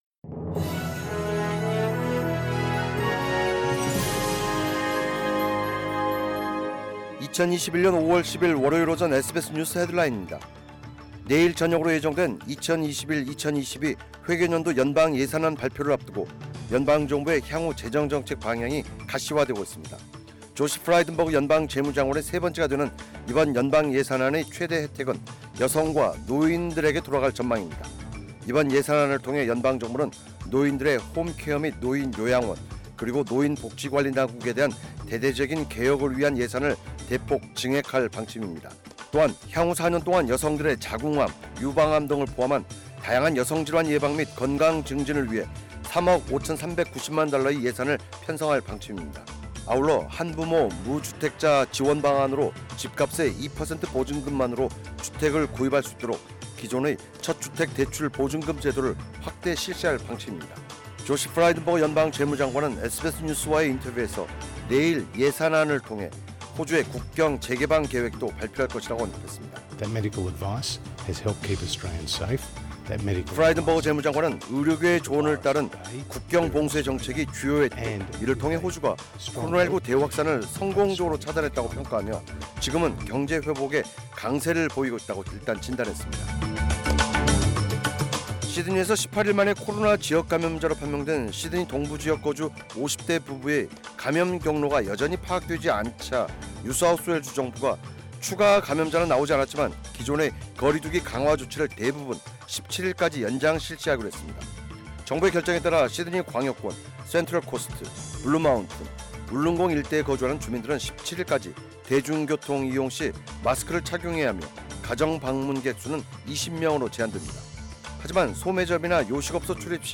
2021년 5월 10일 월요일 오전 SBS 뉴스 헤드라인입니다.
1005-news_headlines.mp3